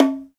Index of /90_sSampleCDs/Roland - Rhythm Section/PRC_Latin 1/PRC_Conga+Bongo
PRC BONGO 6.wav